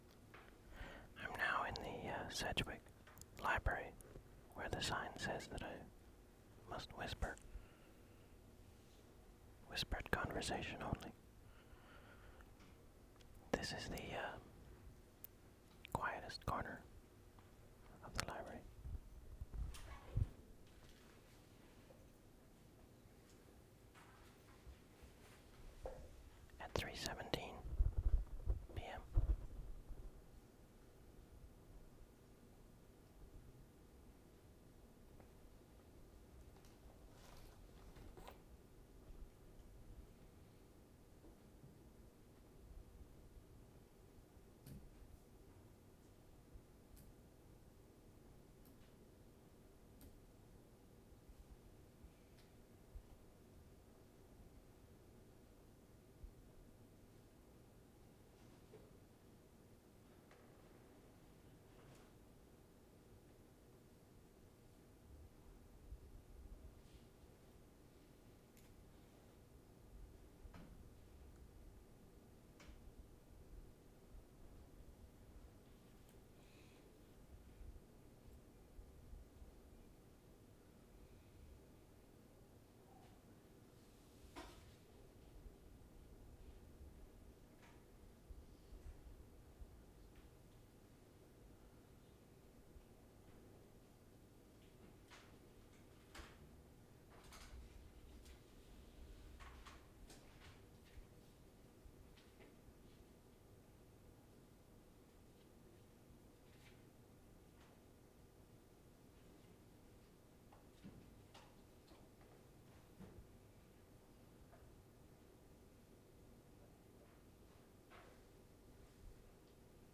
WORLD SOUNDSCAPE PROJECT TAPE LIBRARY
VANCOUVER, WEST SIDE , SEPT 9-10, 1993
UBC Library, Sedgewick study carrells 5:19